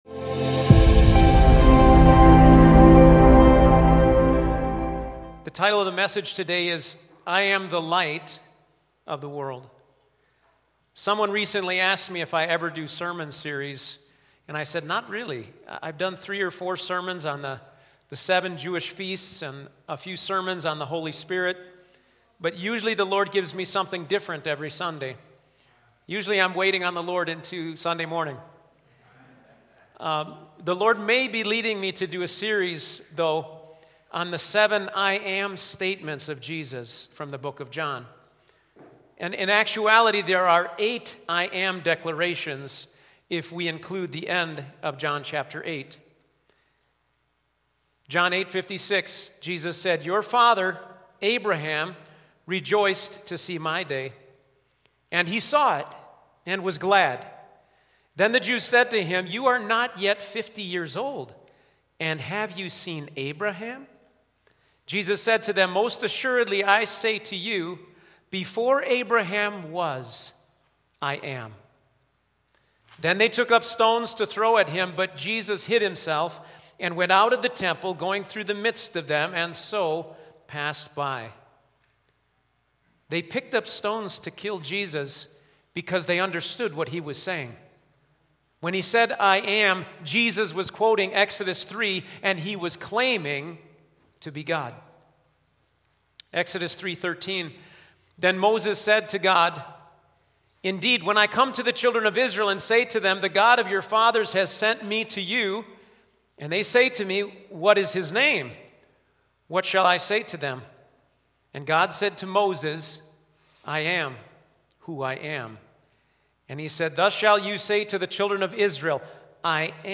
Sermons | Auxano Church